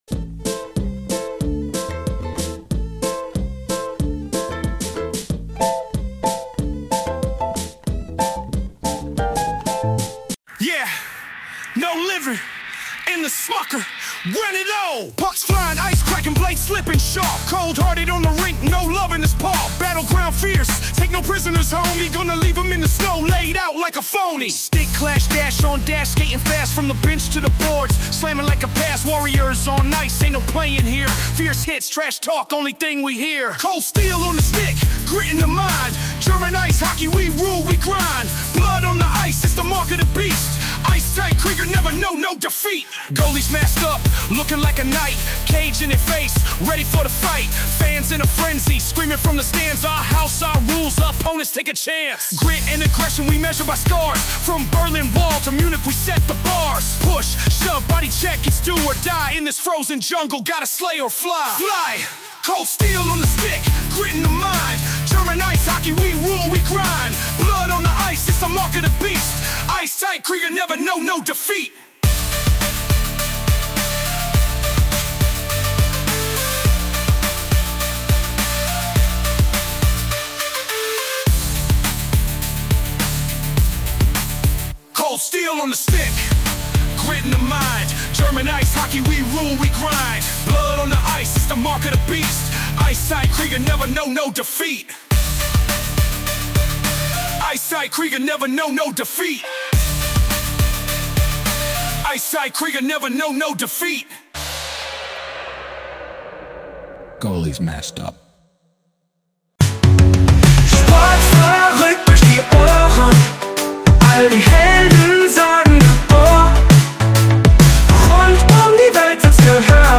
Mein erster Rapsong!!!
sportverrueckt-74-der-rap-eishockey-song.mp3